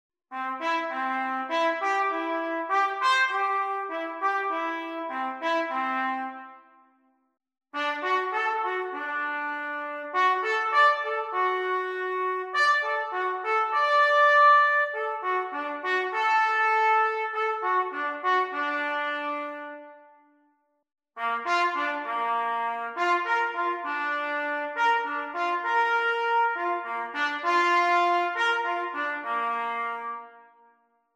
A short extract: Arpeggios